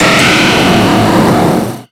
Cri de Métalosse dans Pokémon X et Y.